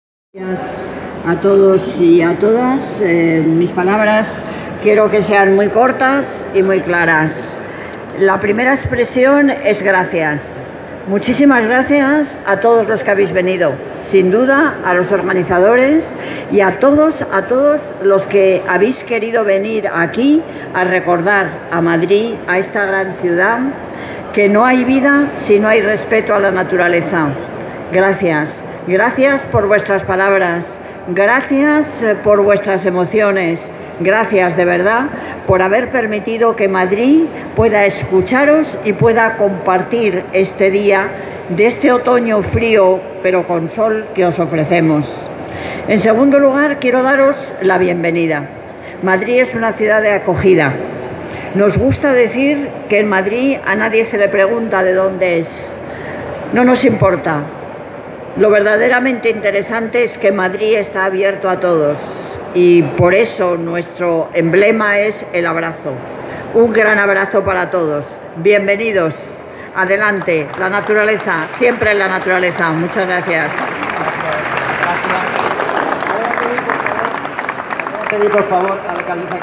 Nueva ventana:Audio alcaldesa, Manuela Carmena
Carmena apertura Expotural.m4a